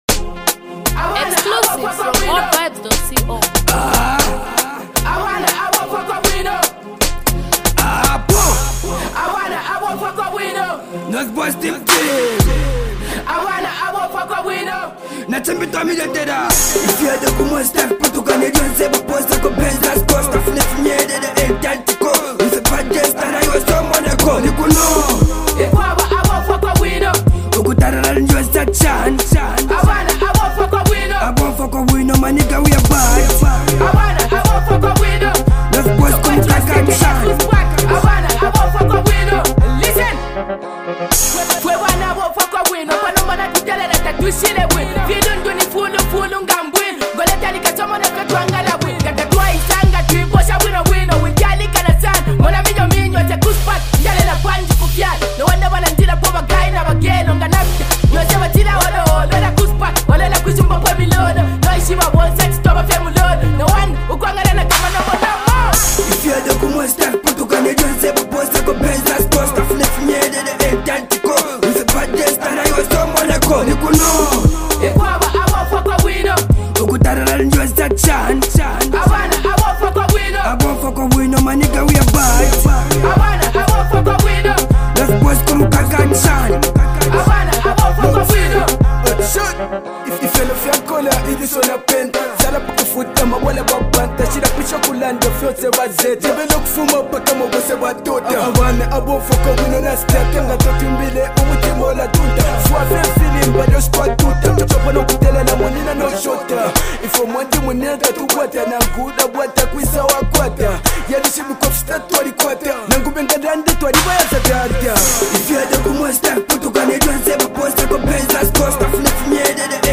Zambian uprising trending duo group